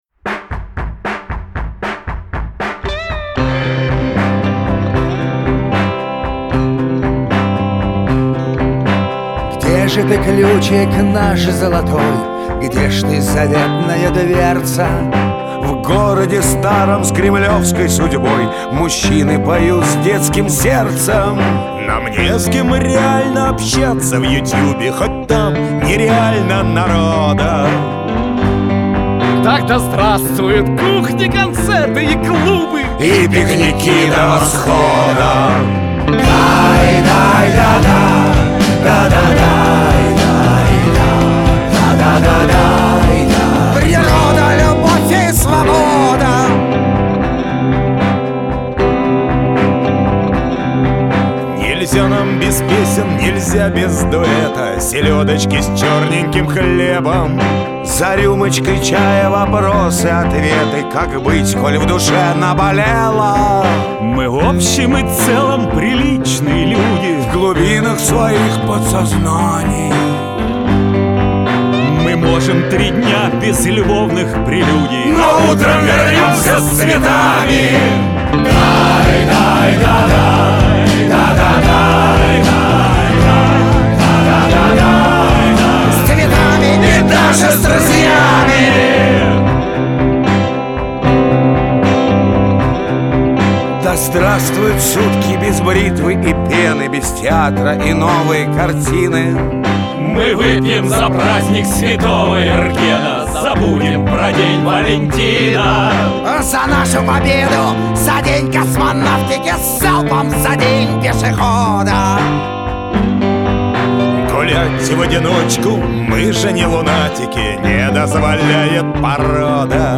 Файл в обменнике2 Myзыкa->Русская эстрада